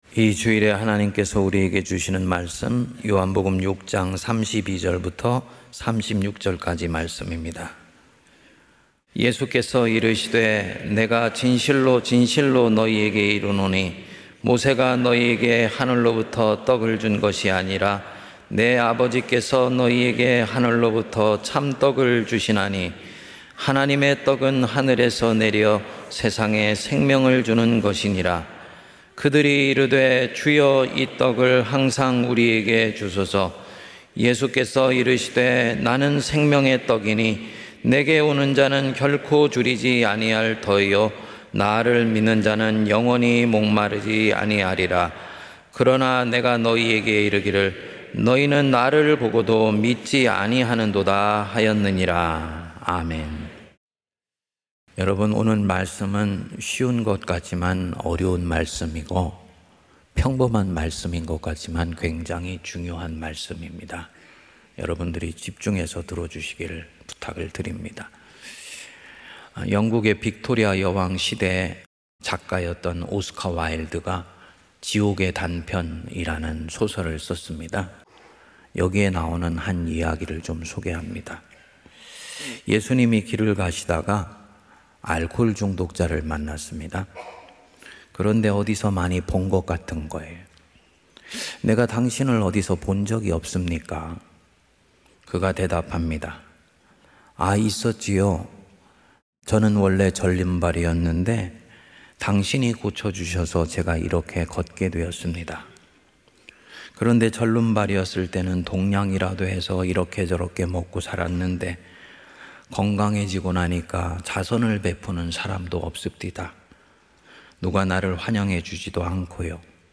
1 Lecture on Genesis (36)